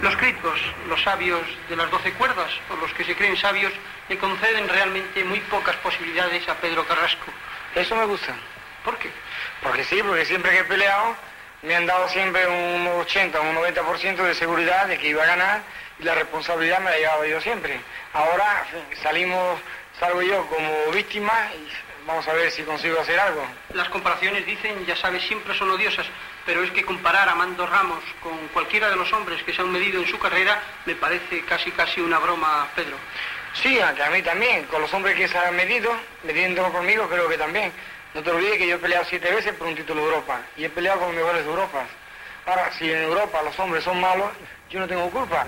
Entrevista al boxejador Pedro Carrasco, abans del combat amb Armando Ramos a Los Ángeles (EE.UU.)
Esportiu